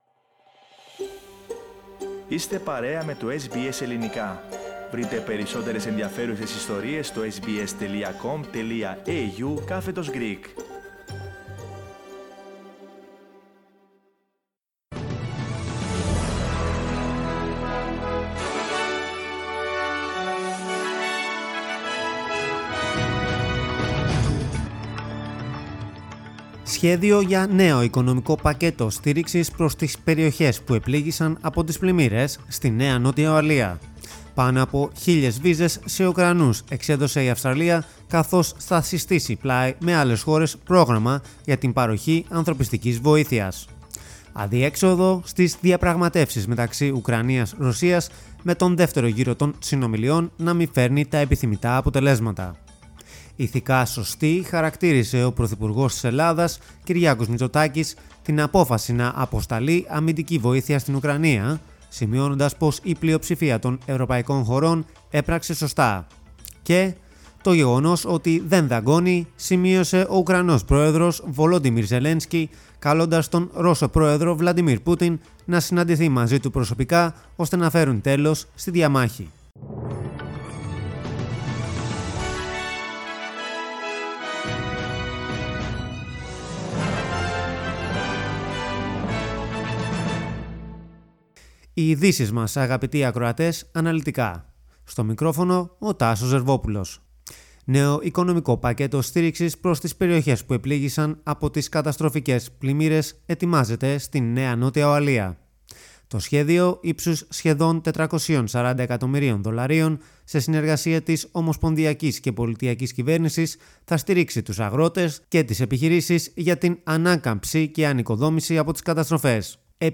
Δελτίο Ειδήσεων - Παρασκευή 04.3.22
News in Greek. Source: SBS Radio